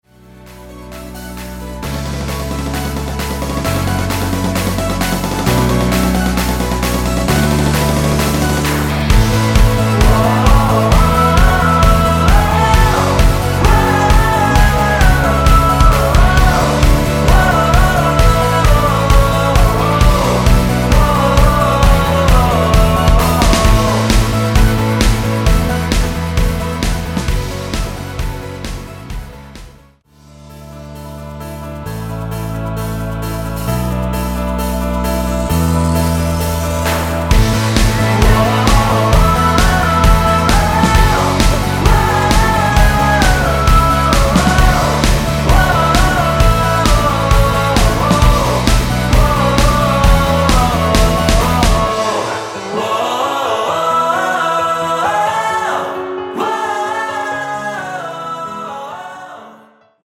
원키 코러스 포함된 MR입니다.(미리듣기 확인)
앞부분30초, 뒷부분30초씩 편집해서 올려 드리고 있습니다.
중간에 음이 끈어지고 다시 나오는 이유는